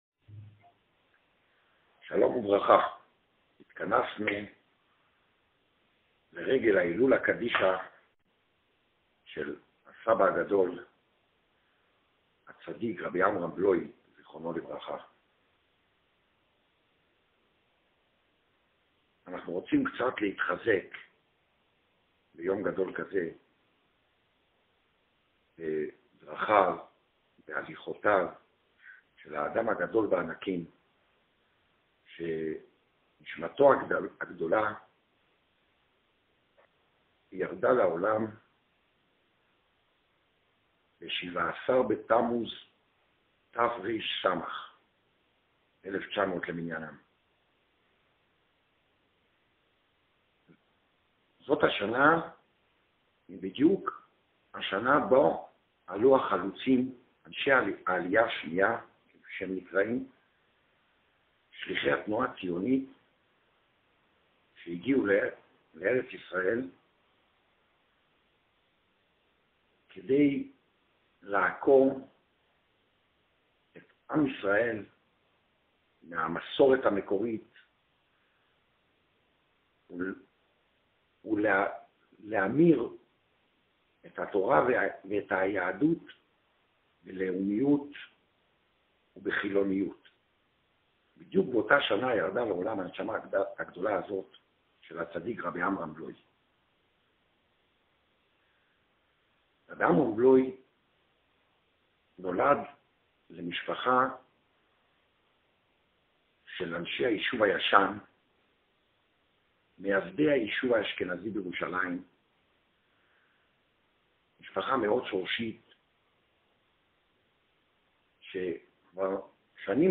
שיחה לכבוד ההילולא
שיחה שנתן אמש בירושלם